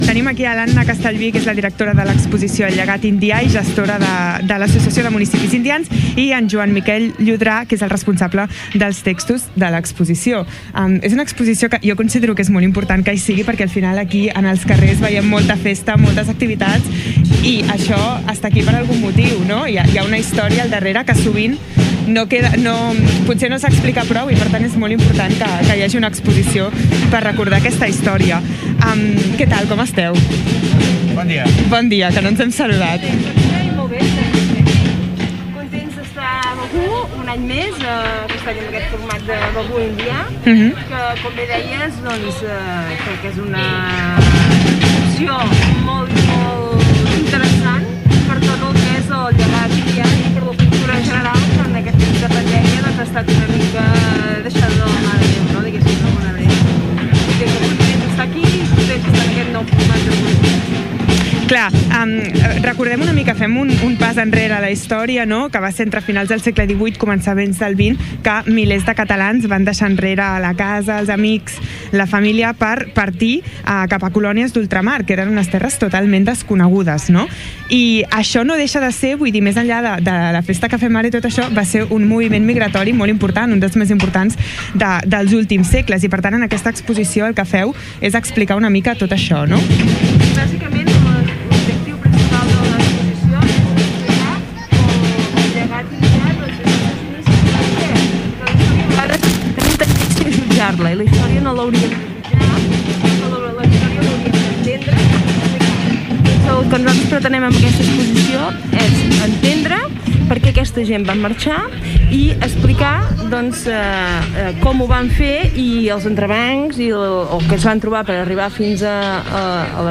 entrevista_exposicio.mp3